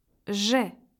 En cliquant sur le symbole, vous entendrez le nom de la lettre.
lettre-j.ogg